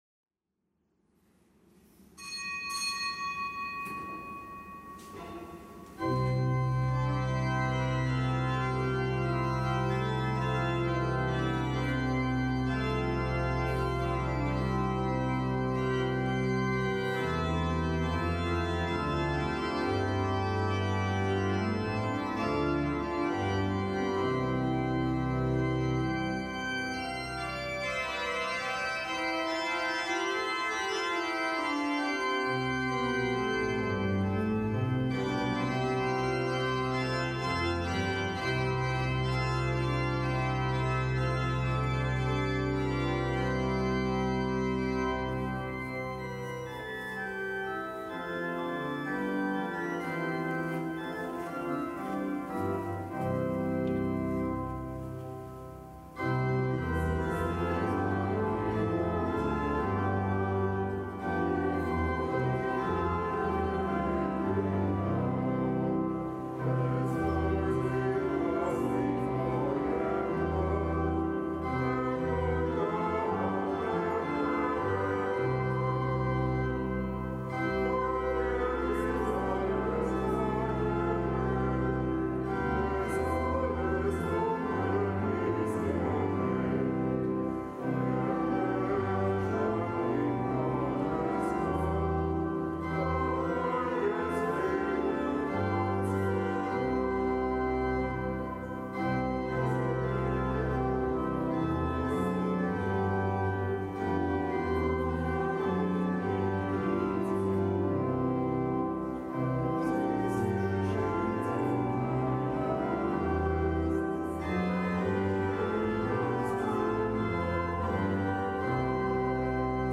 Kapitelsmesse am Fest des Heiligen Markus
Kapitelsmesse aus dem Kölner Dom am Fest des Heiligen Markus, Evangelist